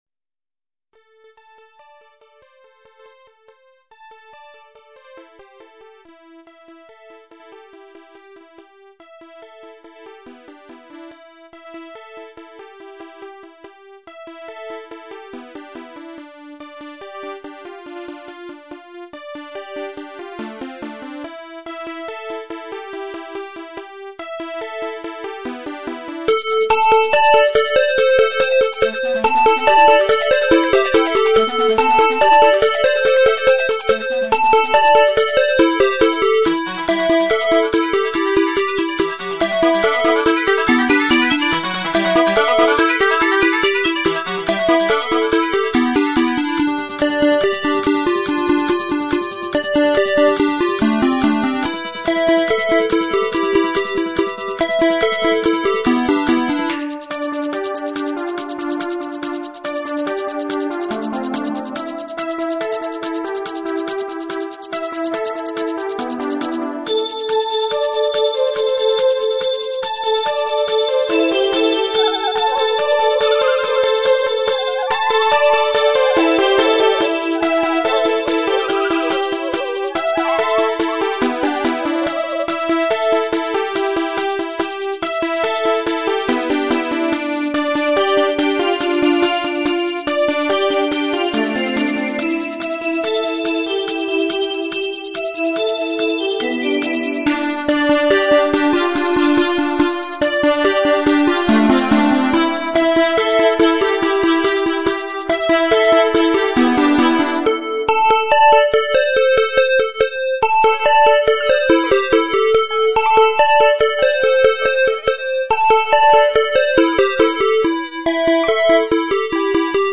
Transformed Midi